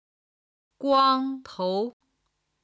今日の振り返り！中国語発声
guangtou.mp3